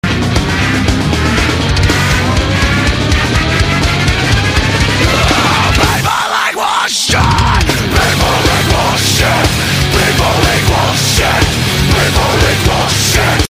А вот еще неплохой звоночек!Громкий!